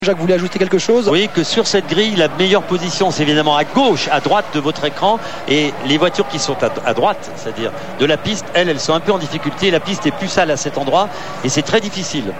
sur TF1
Quadruple bafouillage de Laffite !